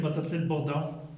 Locution